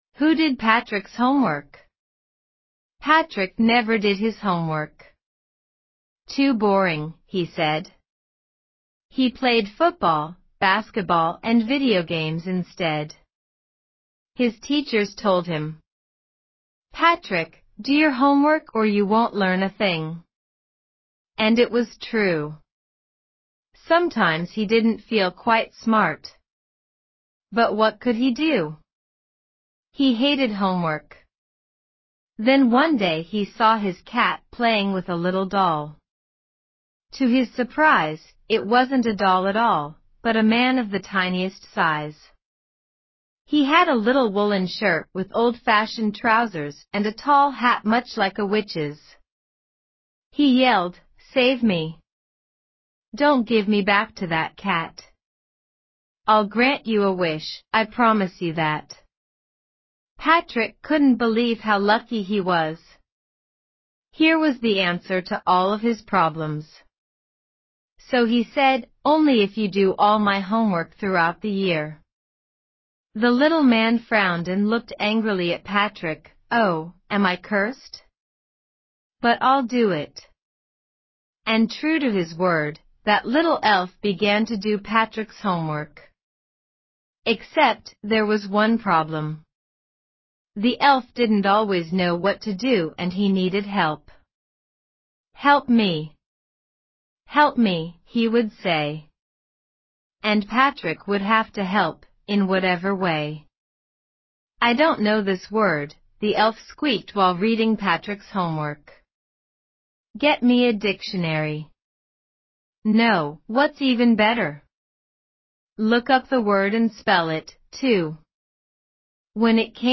朗讀文章音檔1.mp3